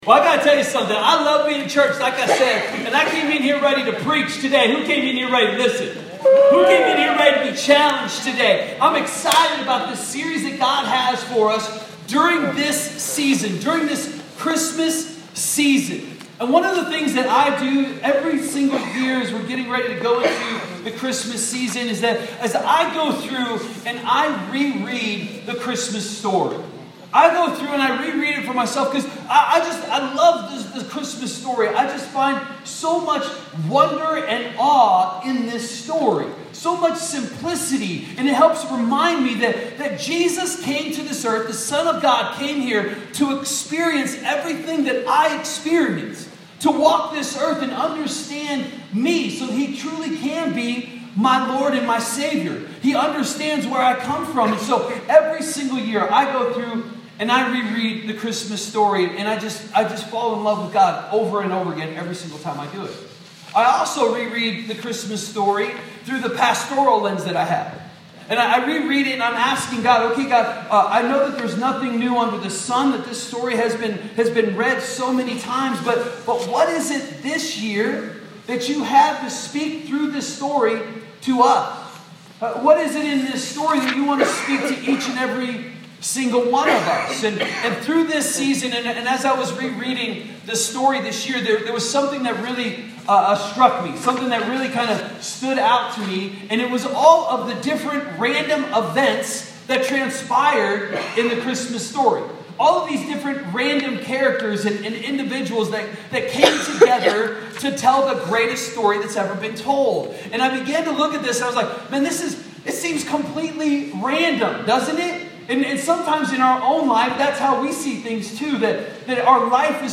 A message from the series "A Random Christmas ."